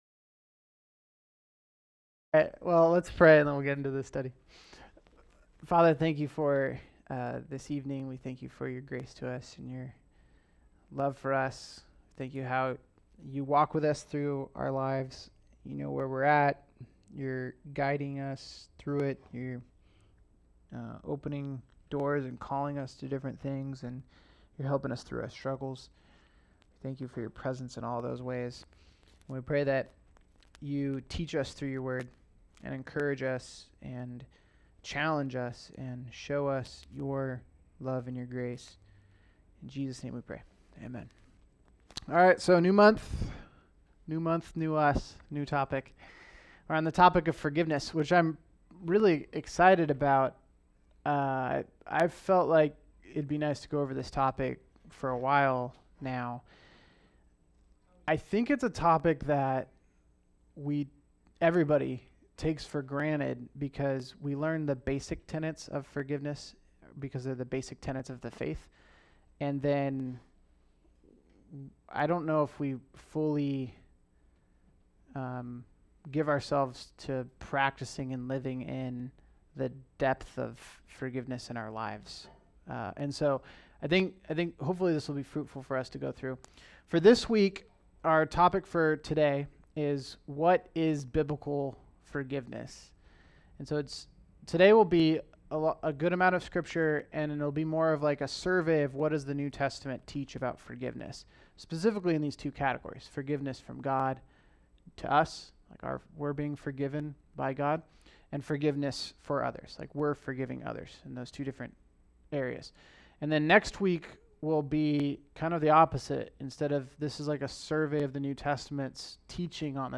All Sermons What is Biblical Forgiveness?